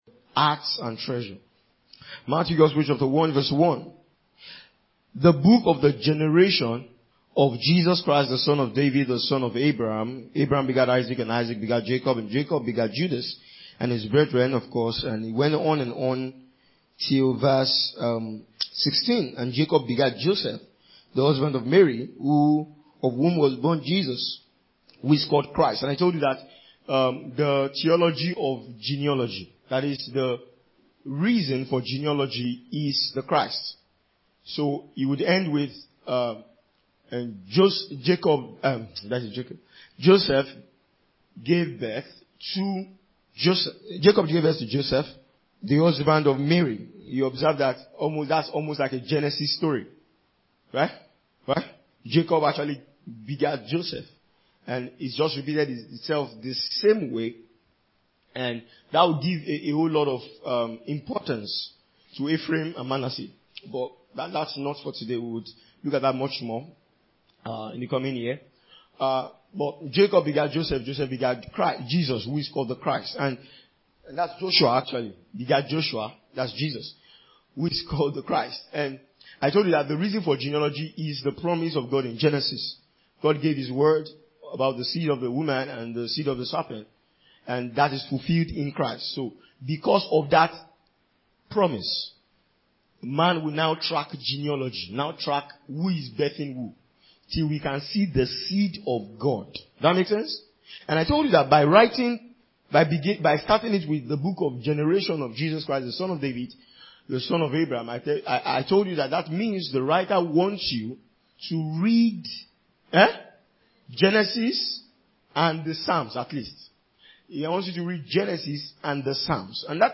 A teaching that addresses the place of the heart in following God. It’s a consecrational teaching that draws lessons from Jesus’ encounter with the rich young man, as it emphasizes what true treasures are, and how what the world offers must never take our eyes away from God’s will.